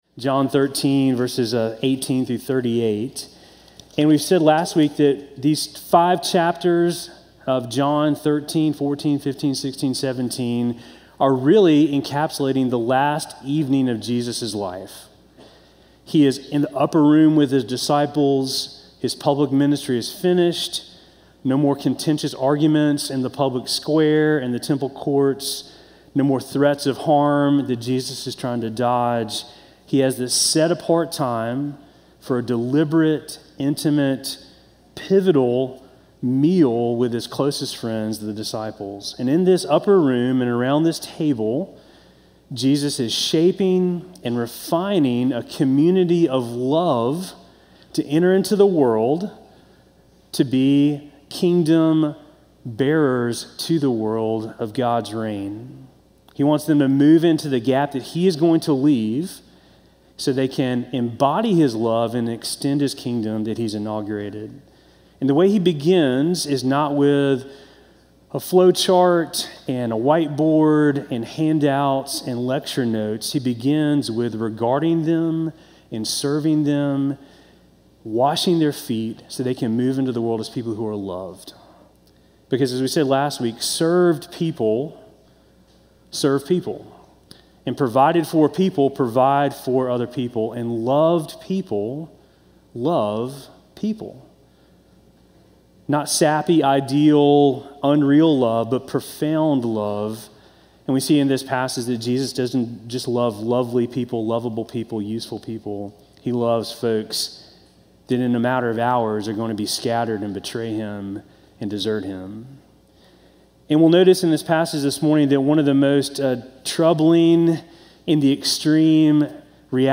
Sermon from January 18